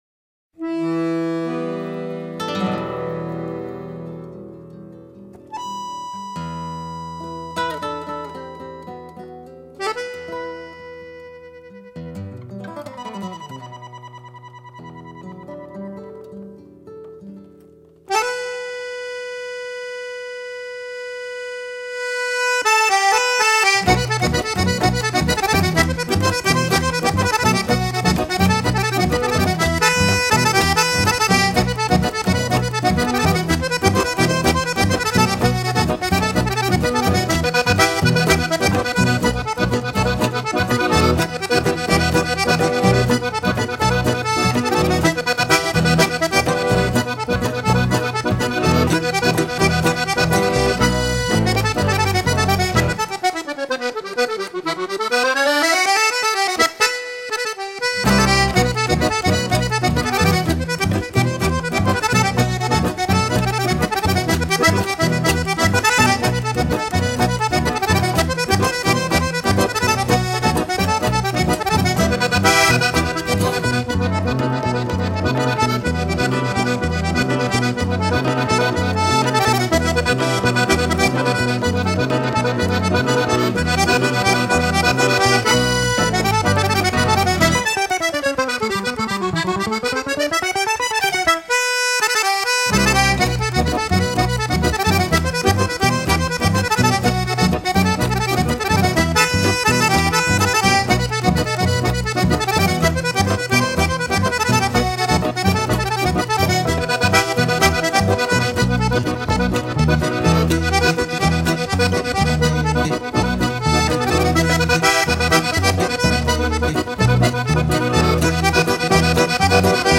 一开始就引人地配以绝妙的打击乐、鼓乐，完美的巴西风韵